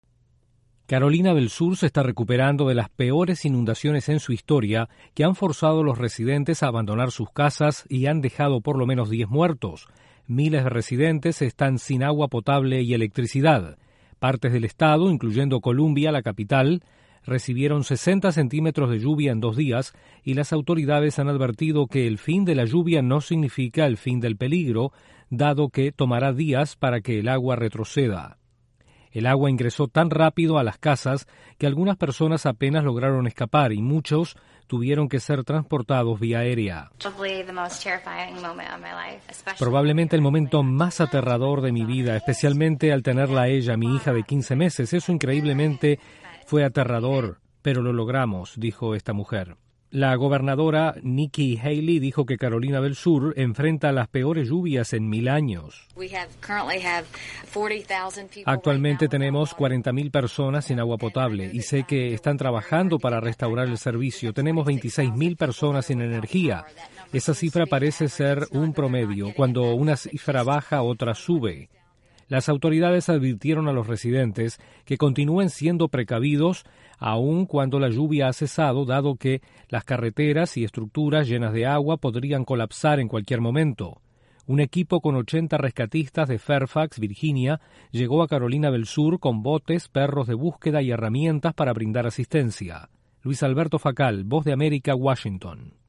El sur de Estados Unidos se recupera de mortales inundaciones. Desde la Voz de América en Washington informa